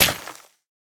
Minecraft Version Minecraft Version latest Latest Release | Latest Snapshot latest / assets / minecraft / sounds / block / suspicious_gravel / break3.ogg Compare With Compare With Latest Release | Latest Snapshot